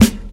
00's Sharp Acoustic Snare Sound G# Key 472.wav
Royality free snare sample tuned to the G# note. Loudest frequency: 1493Hz